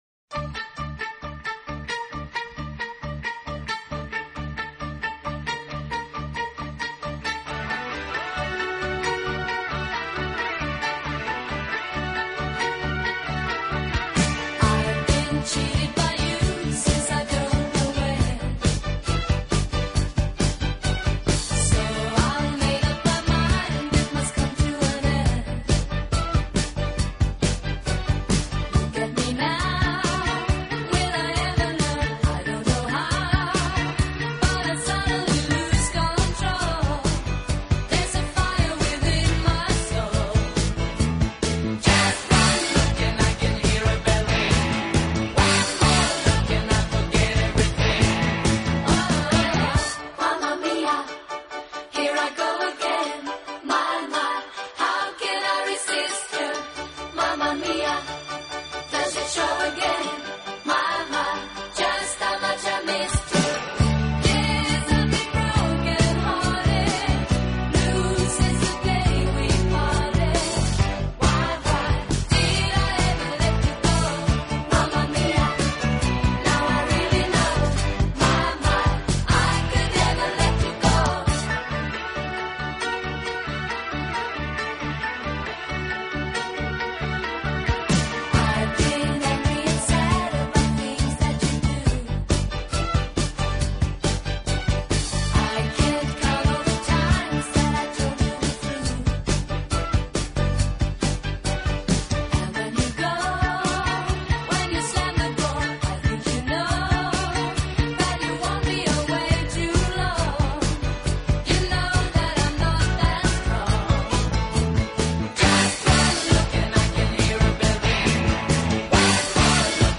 音乐风格：流行|流行/摇滚| (Pop/Rock)